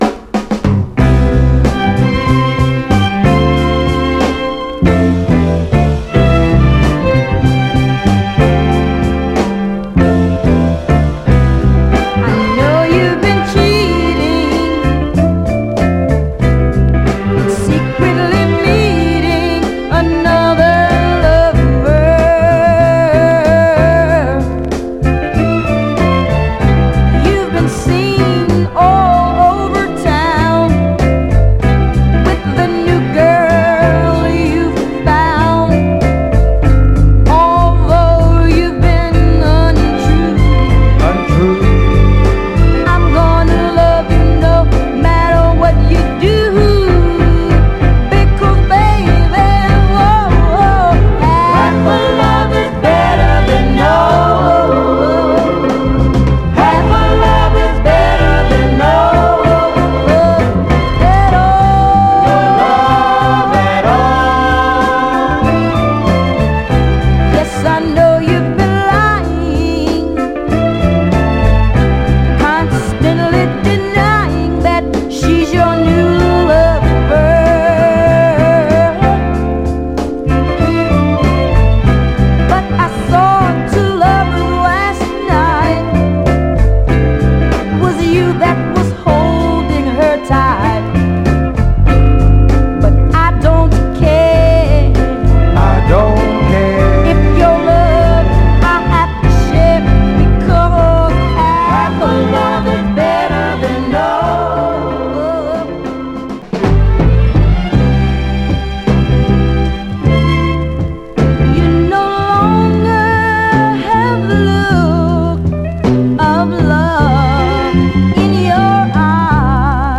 盤は表面的なジュークボックス跡ありますが、グロスが残っておりプレイ良好です。
※試聴音源は実際にお送りする商品から録音したものです※